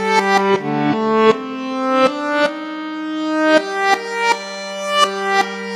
Reverse Bagpipe.wav